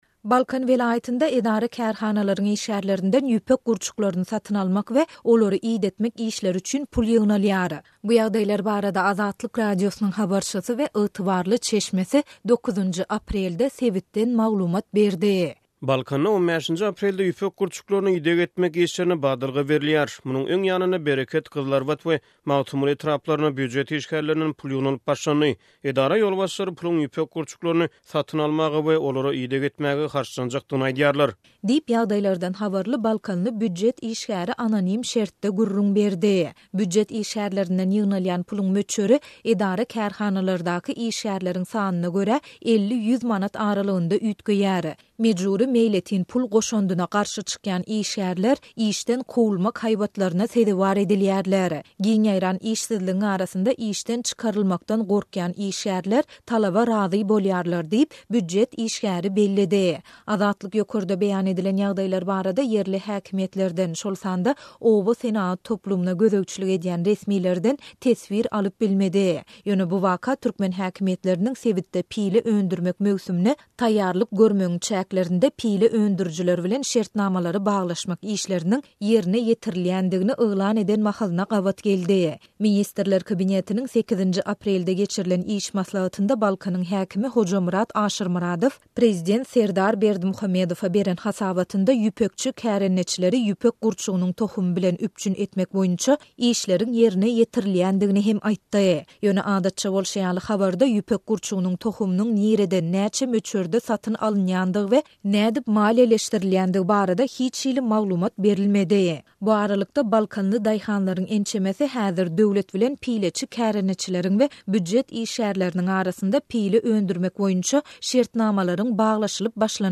Balkan welaýatynda edara-kärhanalaryň işgärlerinden ýüpek gurçuklaryny satyn almak we olary idetmek işleri üçin pul ýygnalýar. Bu ýagdaýlar barada Azatlyk Radiosynyň habarçysy 9-njy aprelde sebitden maglumat berdi.